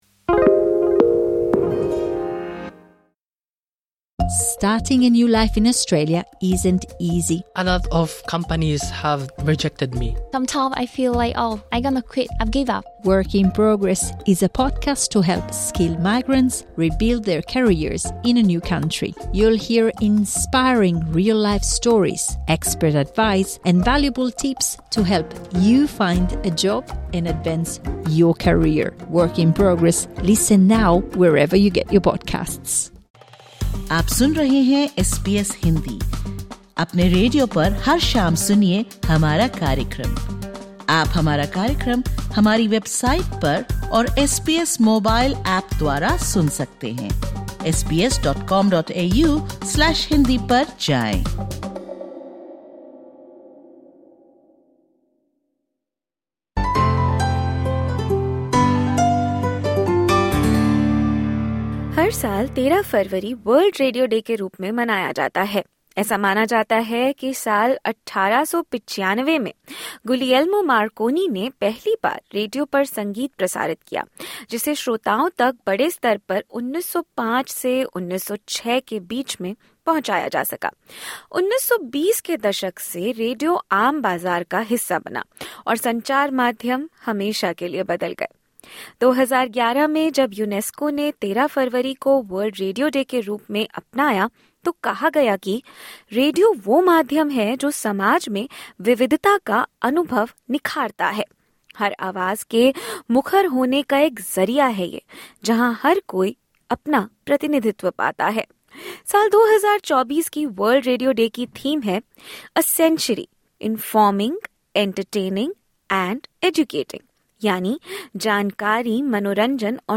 To commemorate this special day, SBS Hindi interviewed some of its regular listeners to understand their connection with the channel as it adapts to the changing preferences in media consumption.